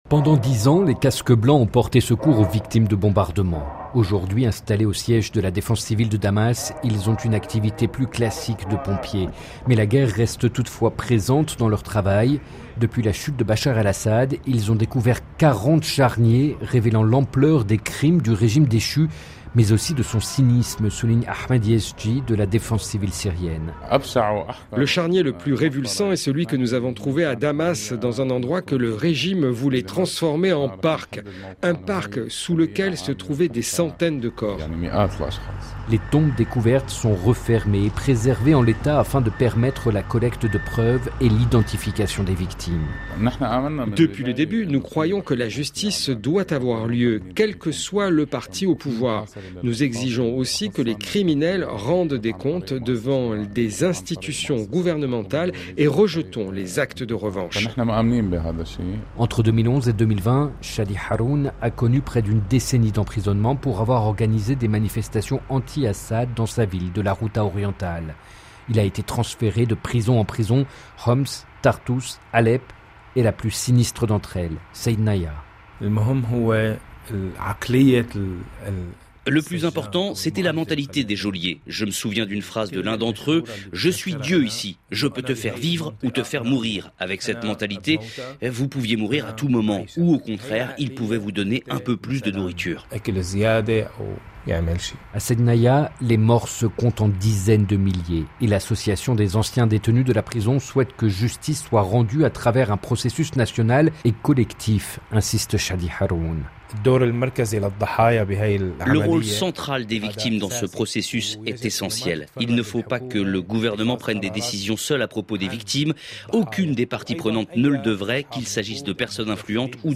Chaque jour, l’illustration vivante et concrète d’un sujet d’actualité. Ambiance, documents, témoignages, récits en situation : les reporters de RFI présents sur le terrain décrivent le monde avec leur micro.